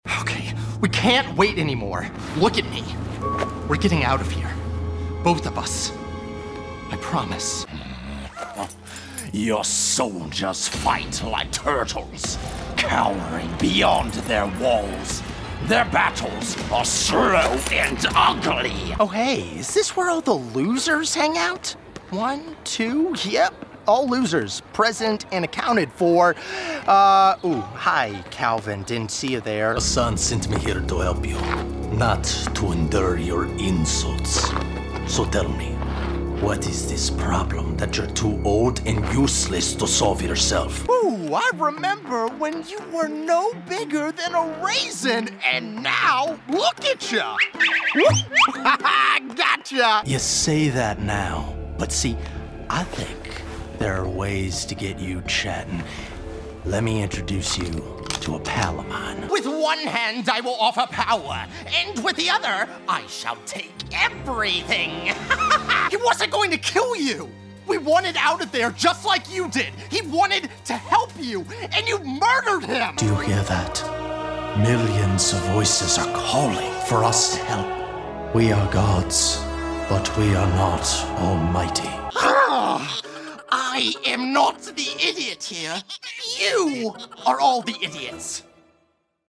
Demos
US (South), British, Irish (General)